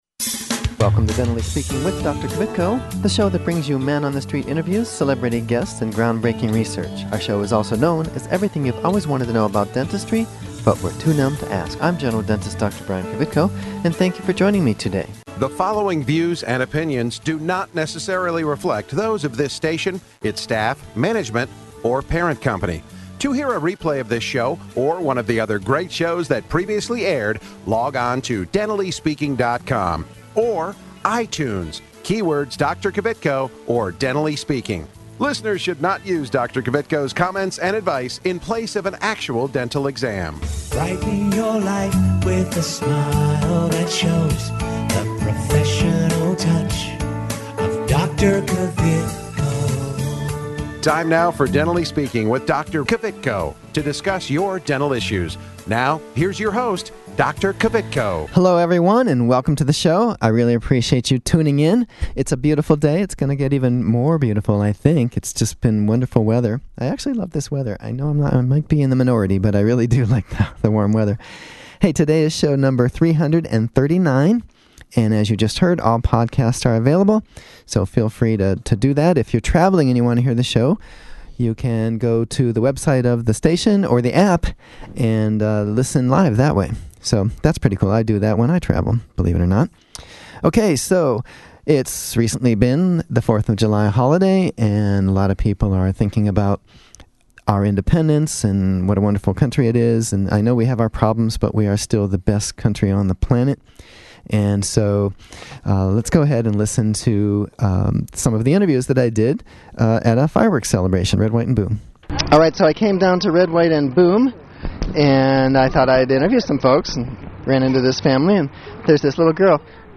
Teeth Across America, Interviews from Red, White & Boom in Columbus, Ohio”